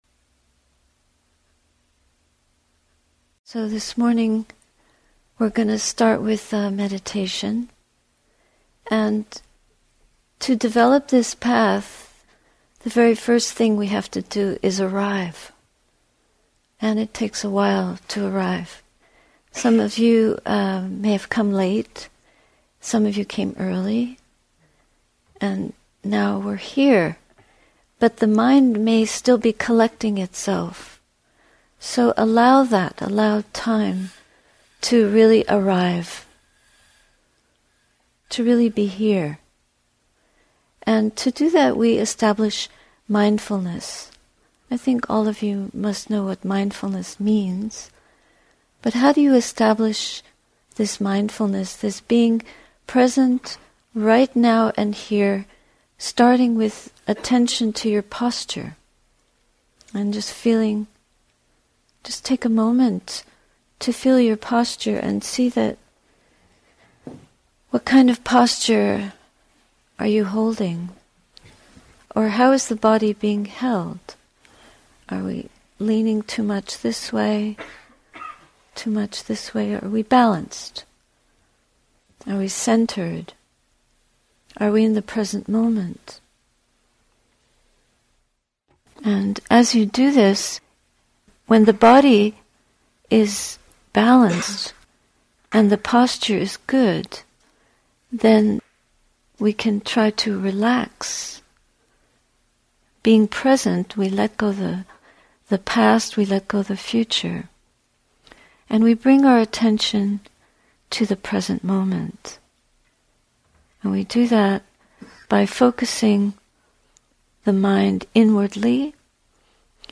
To trust the breath is to let go moment by moment, discovering its hidden truths – and our true home here and now. Guided meditation and reflections, Heart of Wisdom retreat, Insight Meditation Society, Mass. USA, April, 2019. https